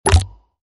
SeedlingHit.ogg